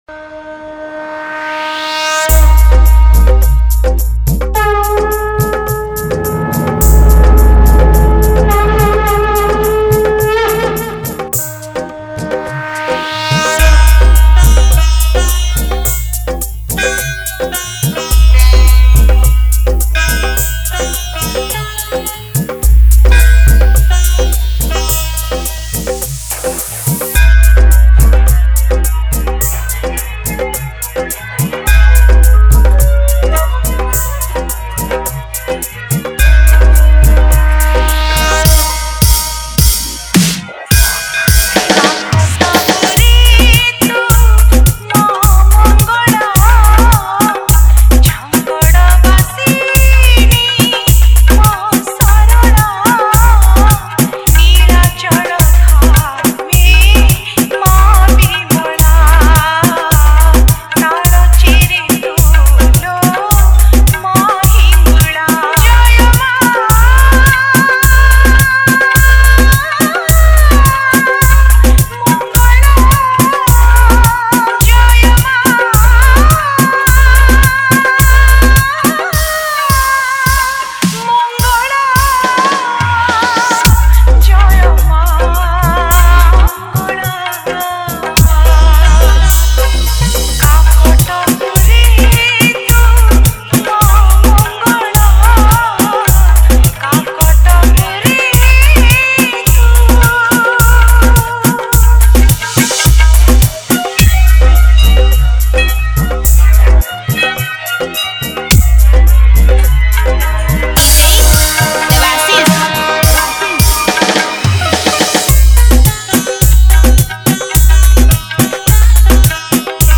Bhajan Dj Song Collection 2022